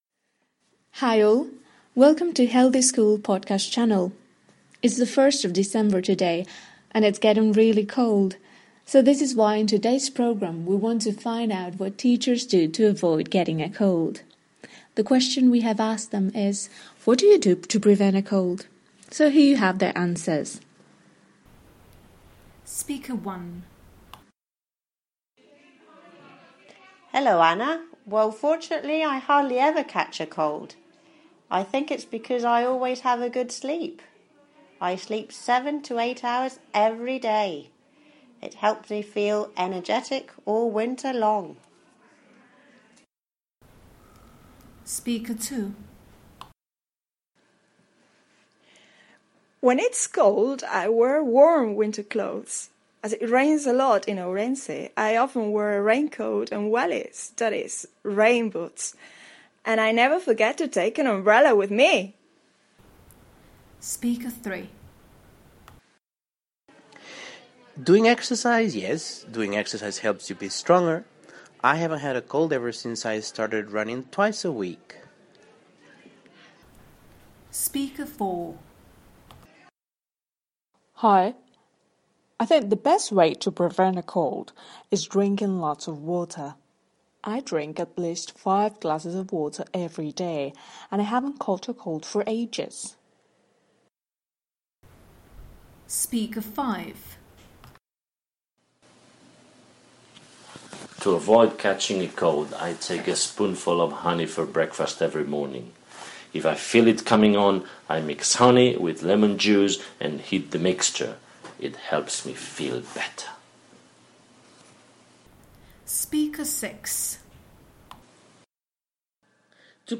Listen to eight teachers explaining how they prevent a cold.